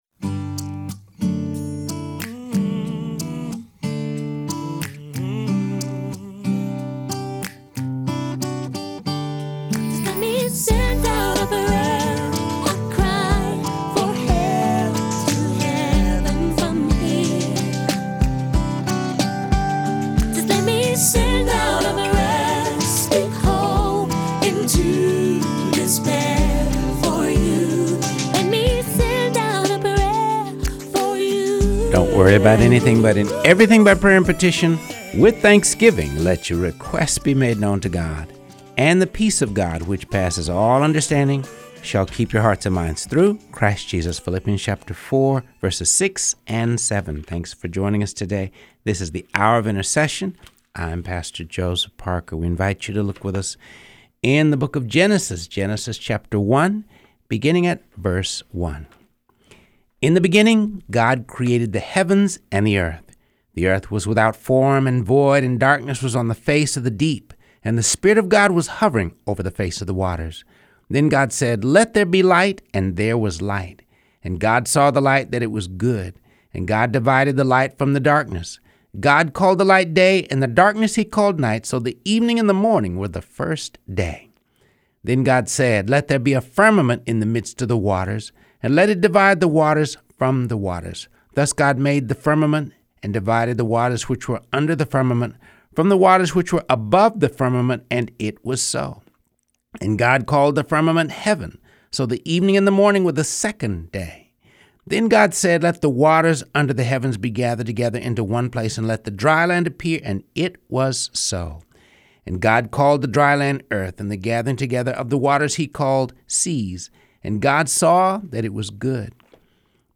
Reading through the Word of God | Episode 92